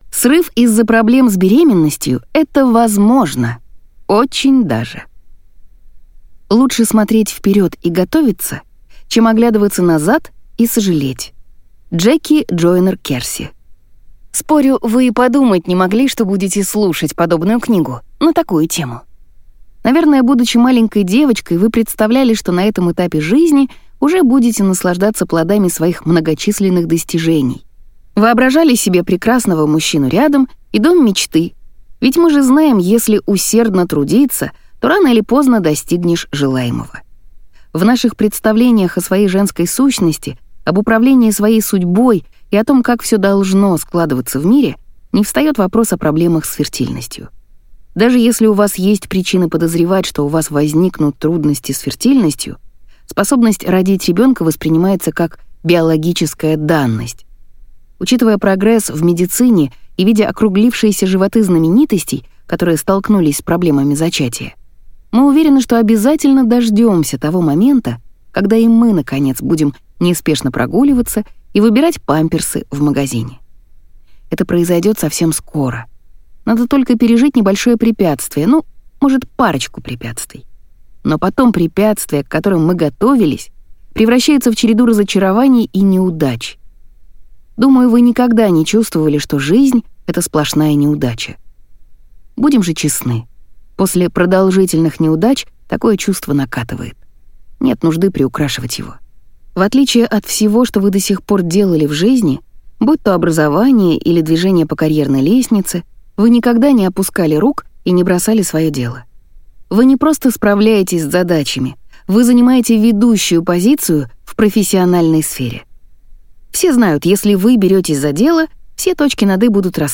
Аудиокнига Я все равно буду мамой. Как убрать психологические блоки, которые мешают забеременеть | Библиотека аудиокниг